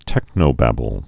(tĕknō-băbəl)